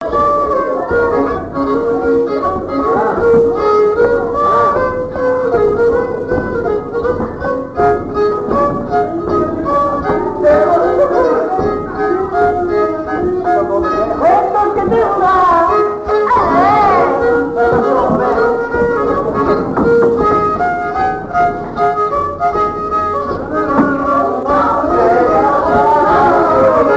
Mémoires et Patrimoines vivants - RaddO est une base de données d'archives iconographiques et sonores.
danse : java
Pièce musicale inédite